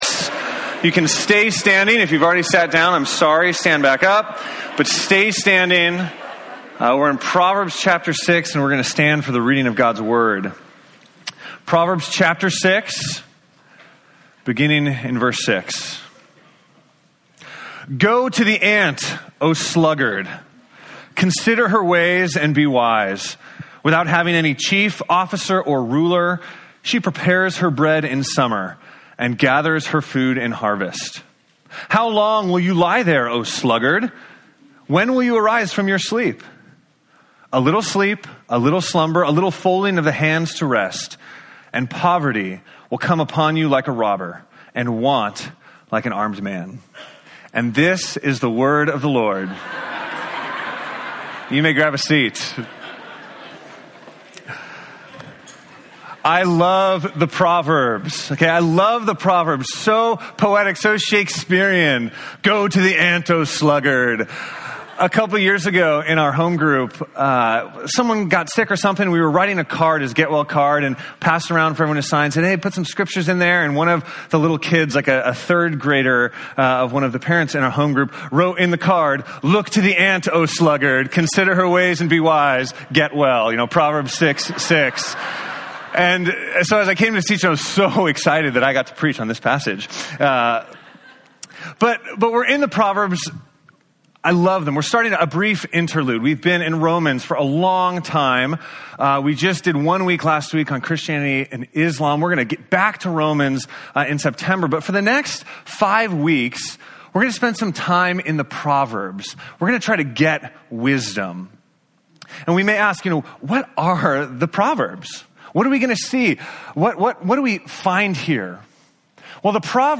Bible Text: Proverbs 6:6-11 | Preacher: